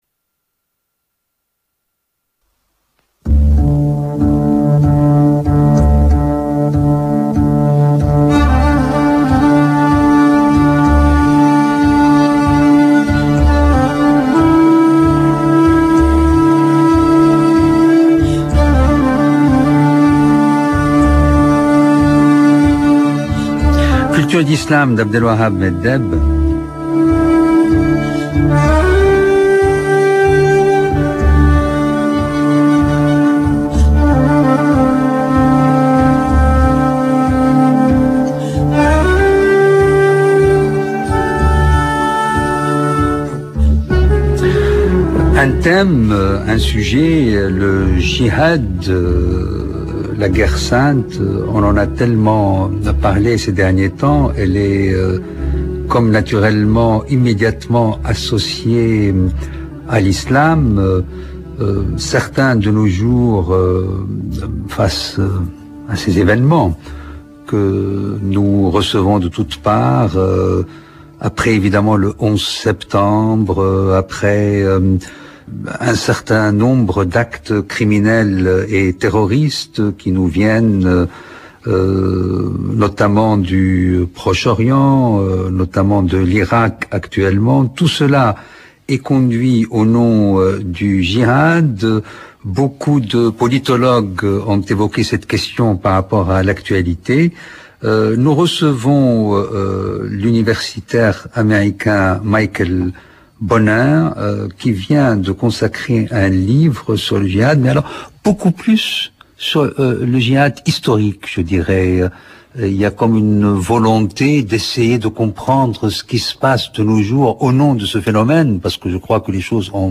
Nous cherchons dans notre conversation � �clairer les effets d�amalgame qui apparaissent dans l�usage actuel de cette notion, aussi bien chez les terroristes islamistes que chez les journalistes et les politologues.